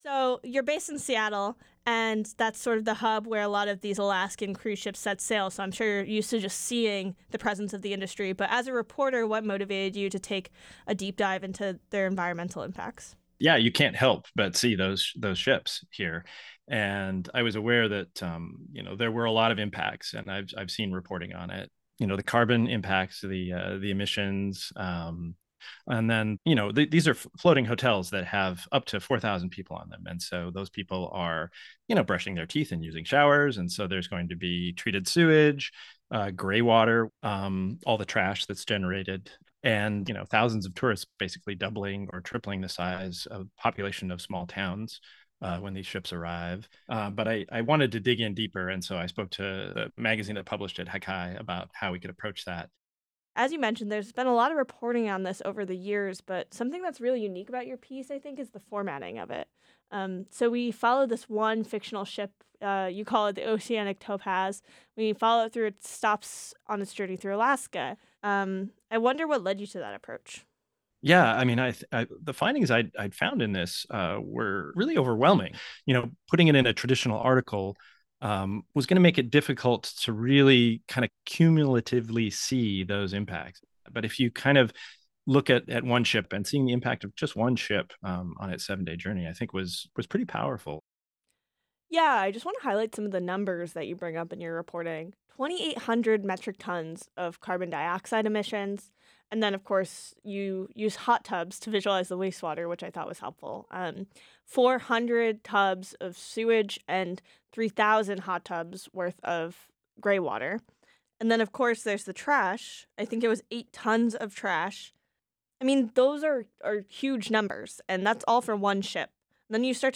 This interview has been edited for size and readability.